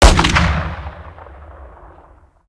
weap_br2_fire_plr_atmo_ext1_06.ogg